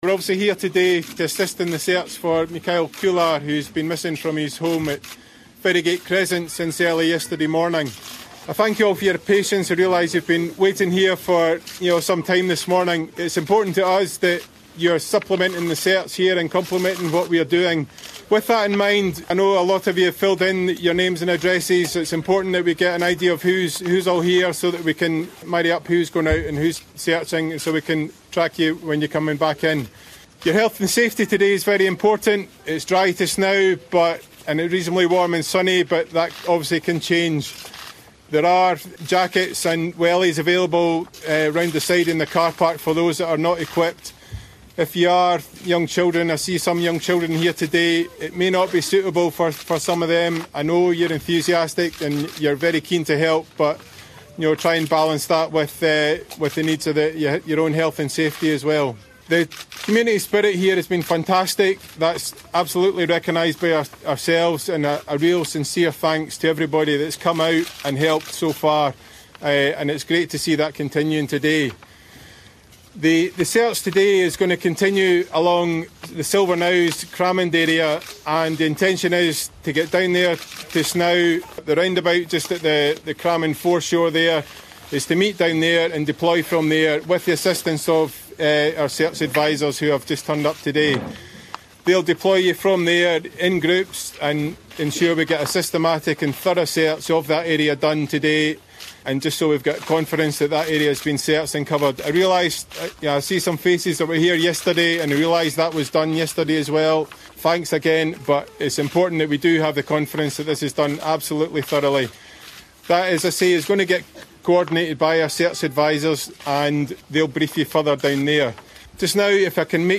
Police have told volunteers gathered in Western Edinburgh that their search will focus along the shoreline at Silverknowes and have thanked them for their support